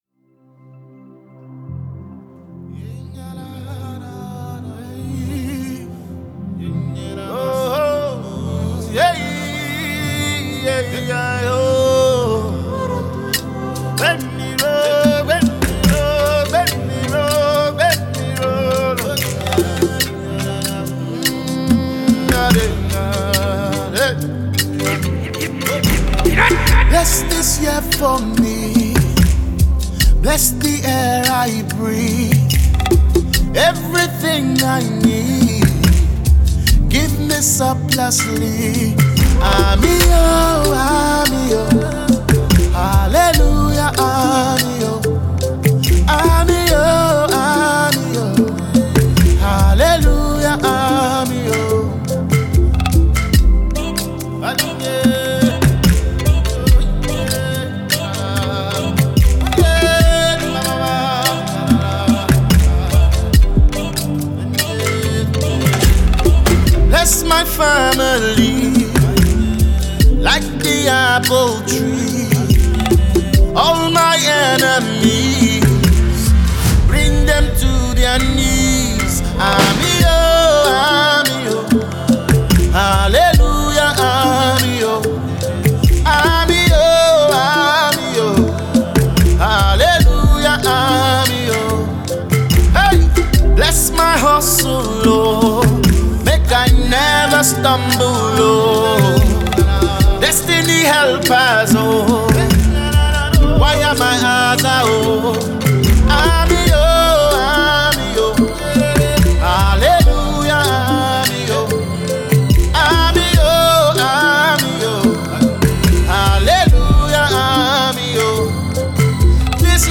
MUSICNaija Gospel Songs